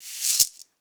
African Shekere 5.wav